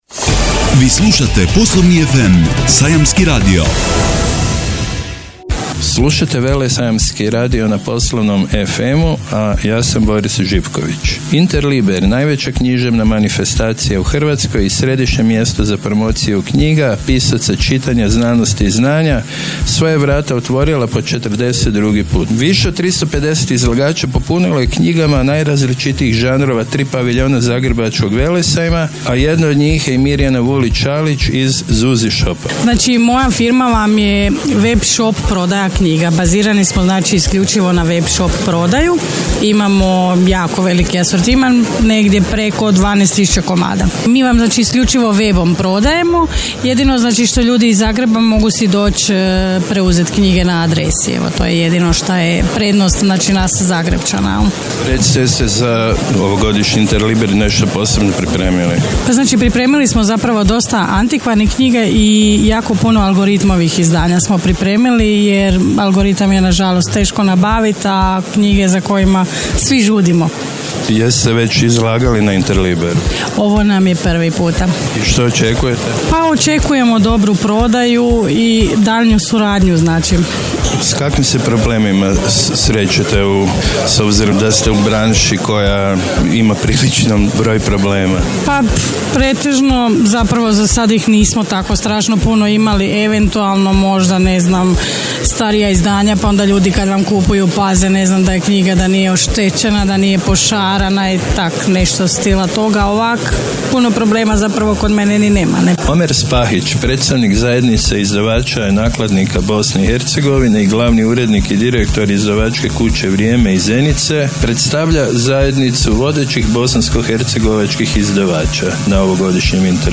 Interliber sajamski radio 1 - poslovniFM